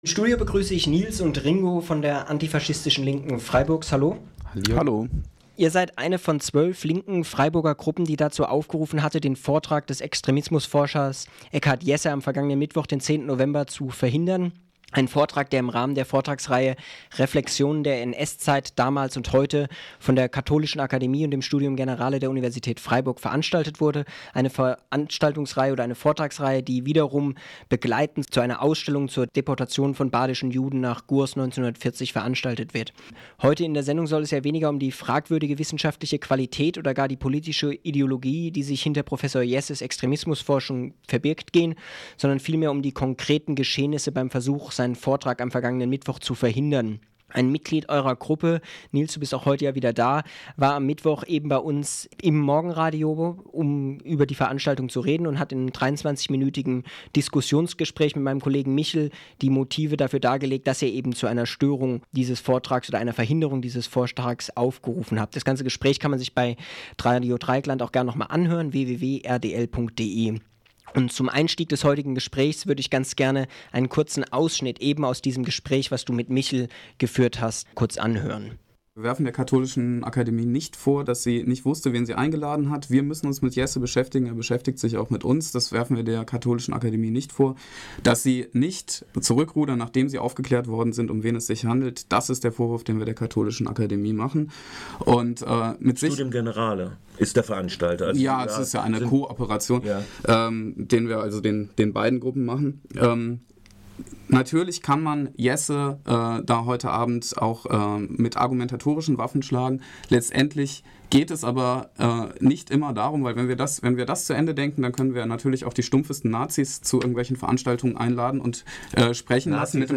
Zwei Mitglieder der Antifaschistischen Linken Freiburgs geben ihre Einschätzung zum Versuch den Vortrag zu verhindern.